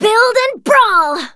jess_start_vo_02.wav